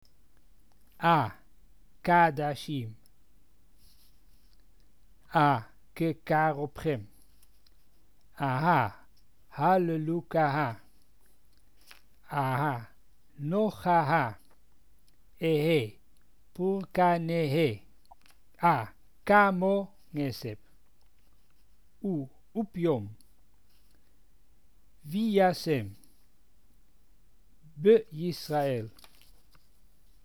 This page describes the rules of pronunciation, as used by the Amsterdam Portuguese community.